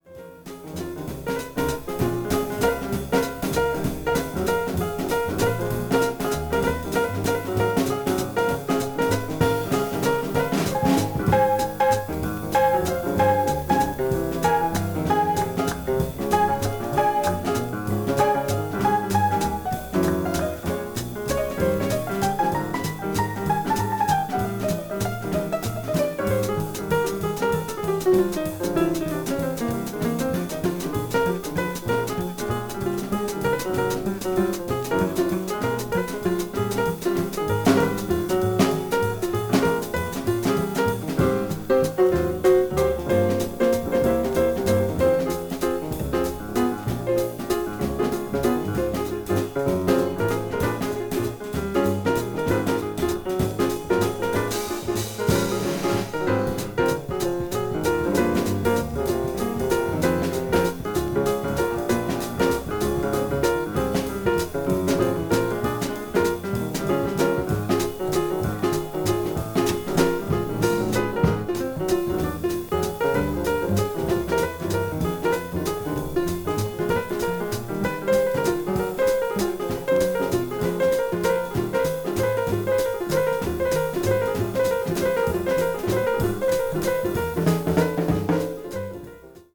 media : EX-/EX-(わずかなチリノイズ/一部軽いチリノイズが入る箇所あり)
modern jazz   post bop